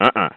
UhUh.mp3